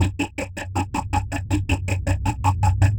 Index of /musicradar/rhythmic-inspiration-samples/80bpm